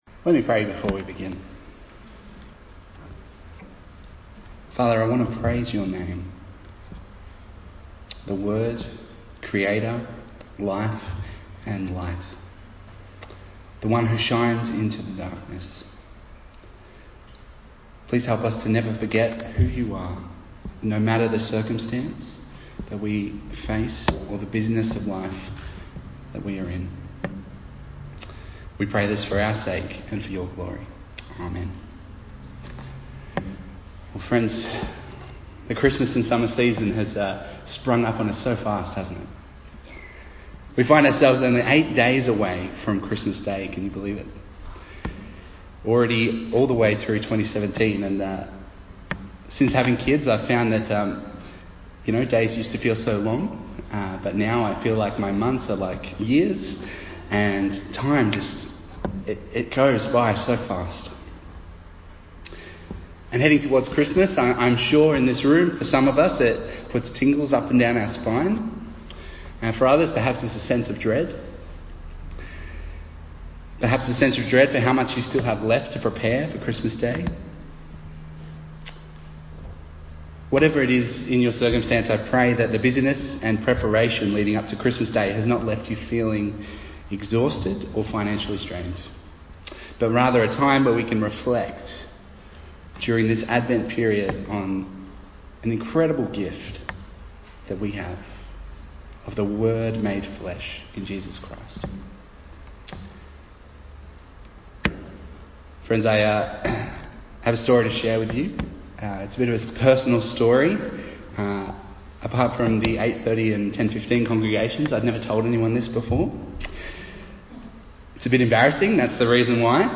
Bible Text: John 1:1-13 | Preacher